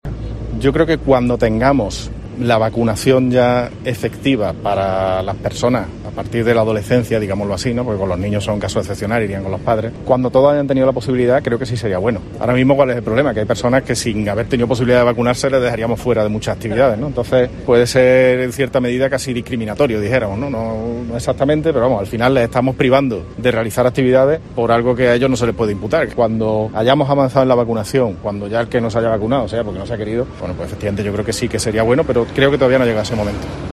En este sentido y en declaraciones a los periodistas, Bellido ha señalado que "cuando hayamos avanzado en la vacunación" y quien no esté vacunado sea "porque no ha querido", entonces "sí sería bueno" el uso del pasaporte Covid.